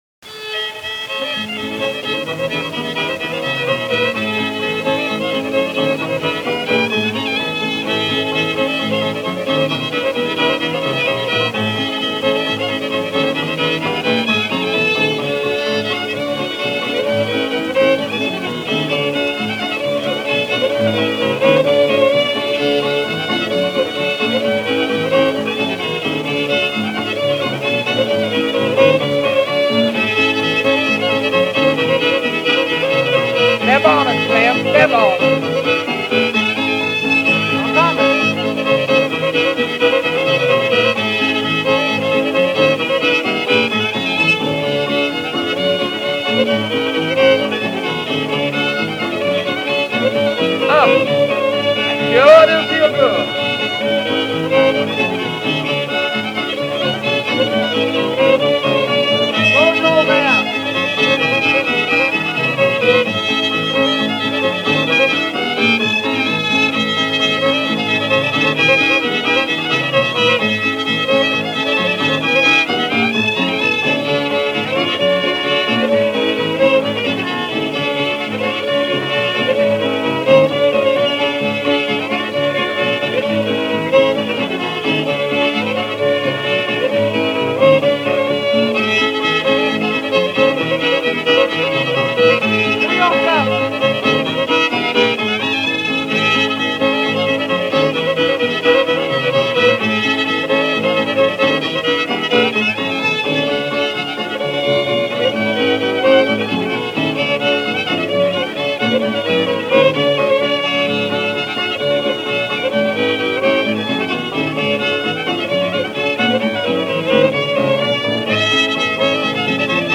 stringband music